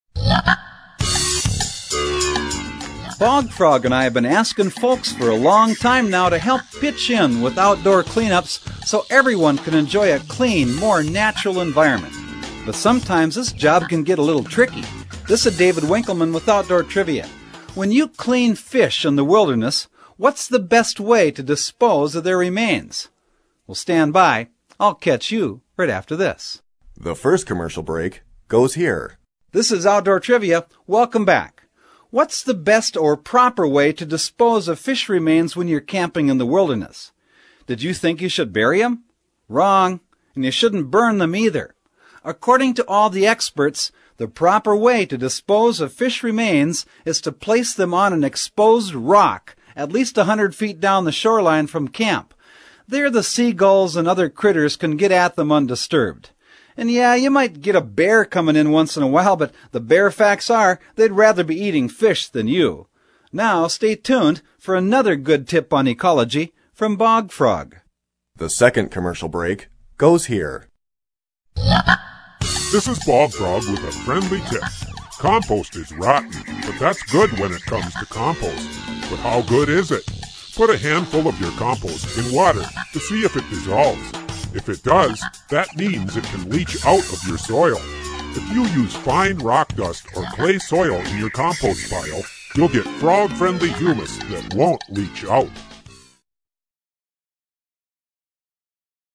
In fact, the question and answer trivia format of this program remains for many people, a most enjoyable, yet practical method of learning.
Bog Frog's voice is distinctive and memorable, while his messages remain positive and practical, giving consumers a meaningful symbol to remember.